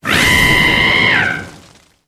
Grito de Cinderace.ogg
Grito_de_Cinderace.ogg.mp3